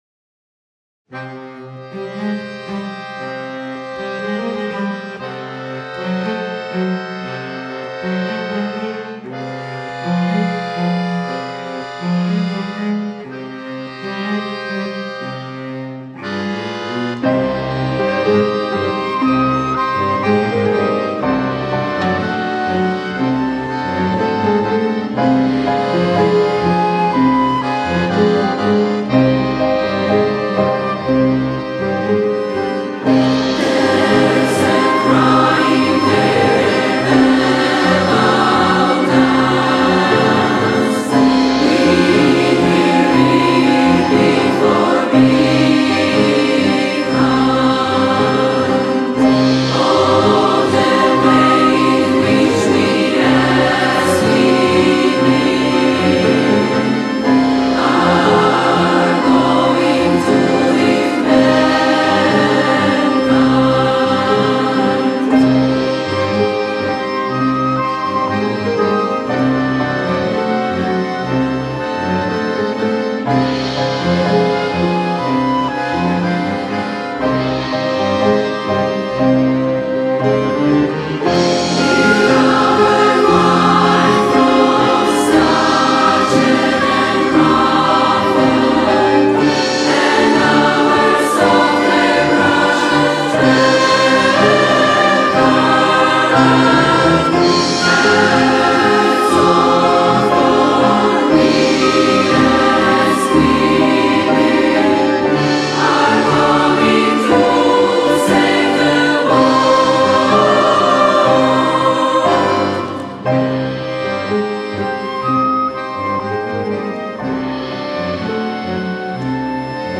18 października 2025 r. w Sali Koncertowej Wydziału Muzyki Uniwersytetu Rzeszowskiego odbyło się międzynarodowe wydarzenie artystyczne o charakterze premierowym, prezentujące widowisko muzyczne pt.
Utwór został skomponowany z myślą o rzeszowskim środowisku chóralnym i akademickim, a jego struktura łączy elementy współczesnej muzyki chóralnej, orkiestrowej oraz komponenty narracyjne właściwe dla muzyki teatralnej.
współczesne kompozycje chóralne, kompozycja chóralno-orkiestrowa